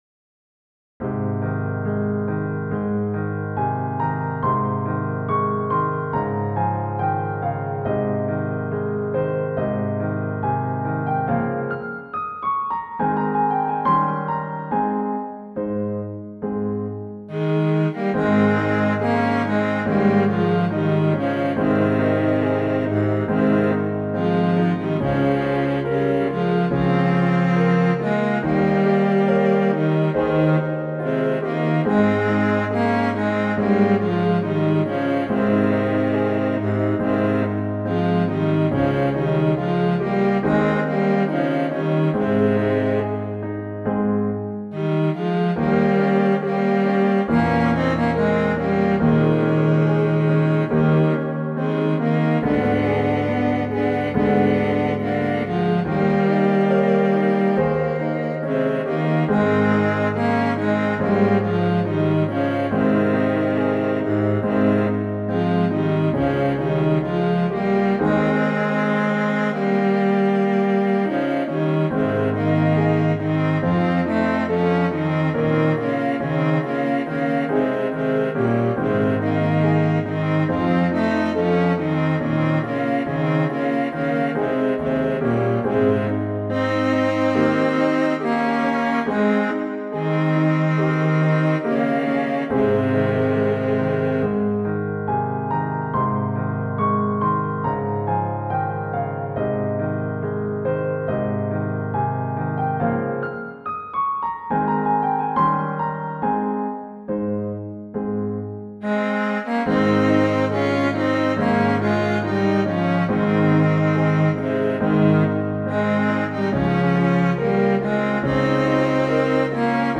Classical Duets